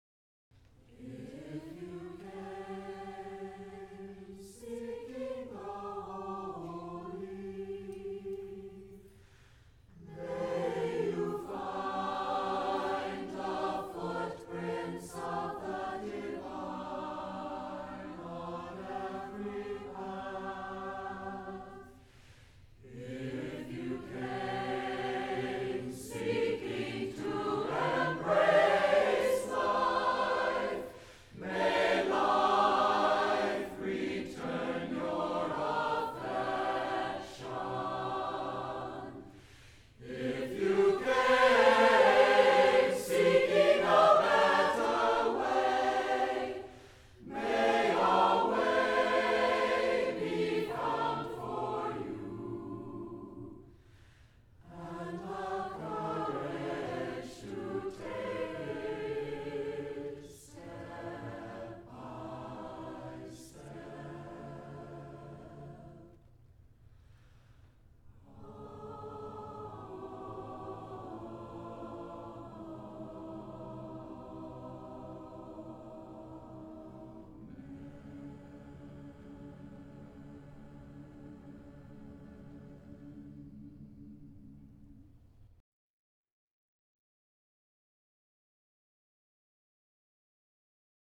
for SAATB a cappella